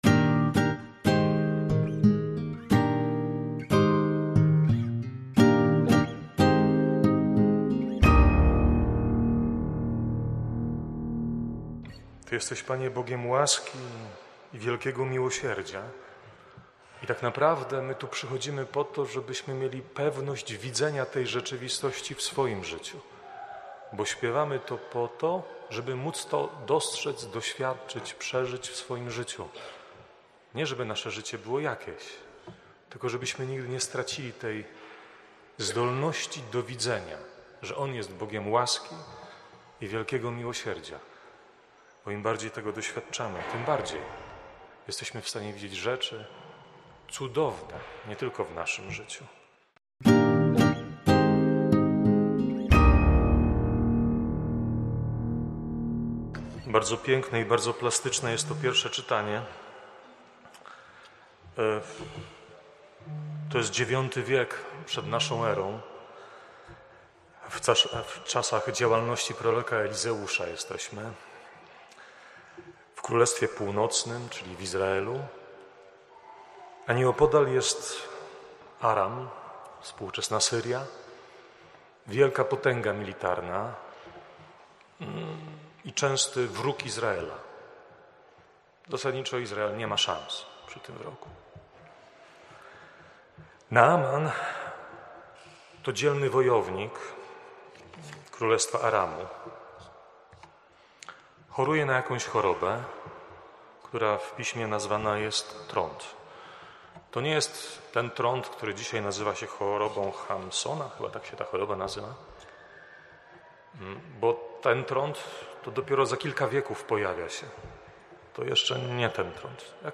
kazania.